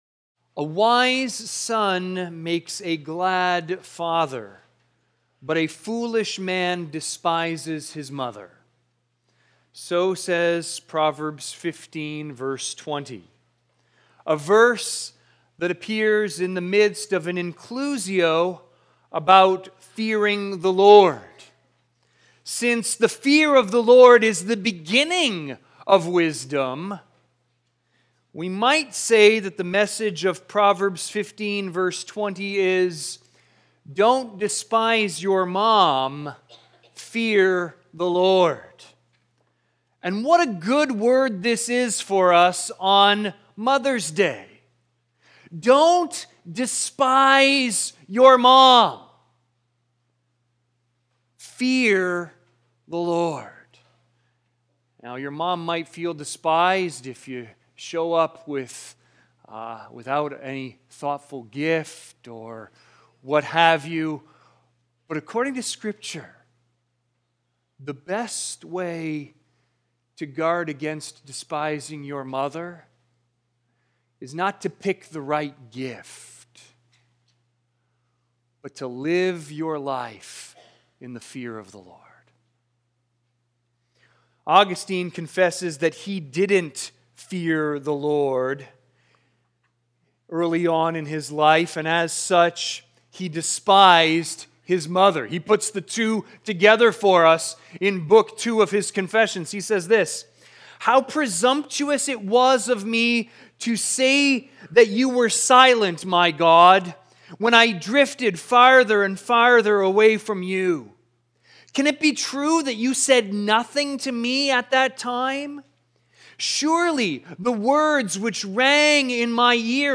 View the Sunday service. cbcwindsor · 2023-05-14 Sunday Service